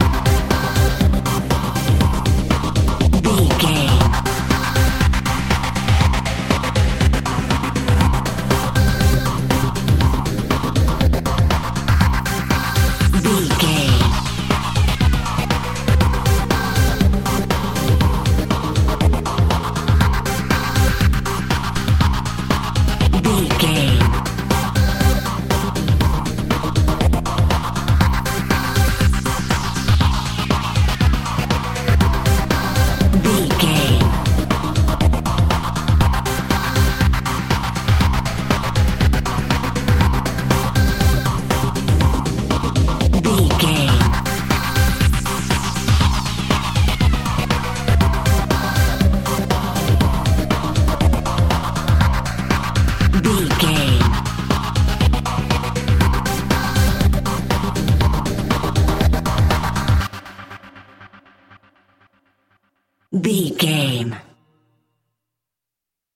Epic / Action
Aeolian/Minor
drum machine
synthesiser
electric piano
Eurodance